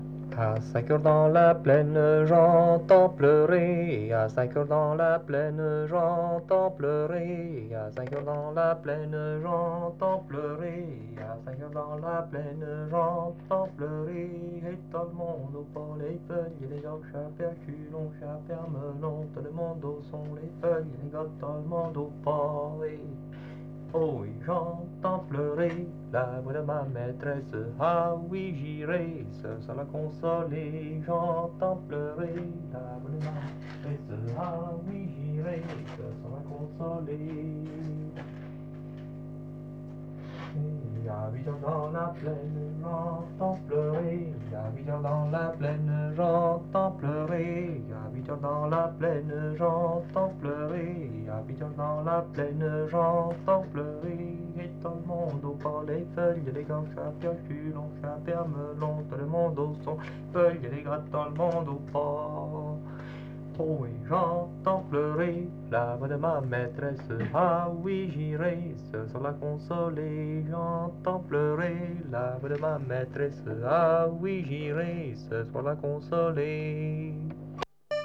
grand-danse
danse : ronde : grand'danse ; gestuel : à marcher ;
circonstance : fiançaille, noce ;
Répertoire à l'accordéon diatonique
Pièce musicale inédite